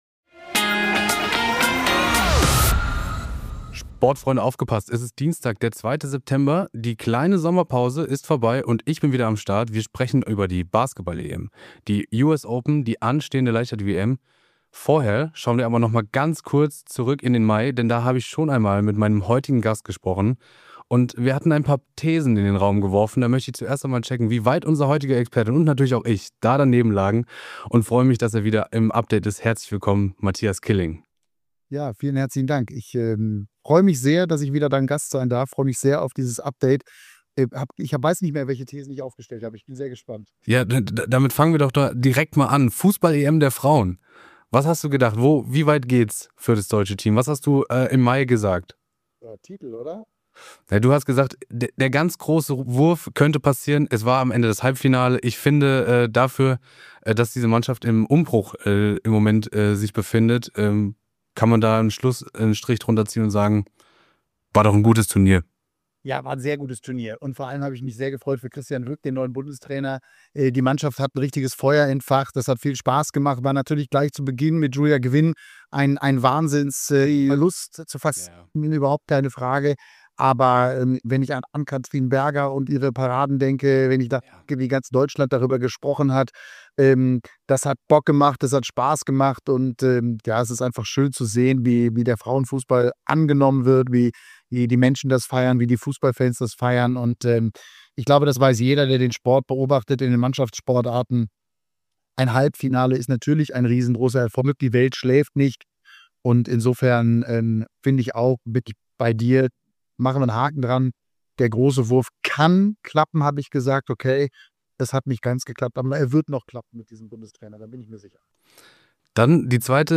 Kein anderer ist dafür besser geeignet als Matthias Killing als heutiger Gast im Team D Update.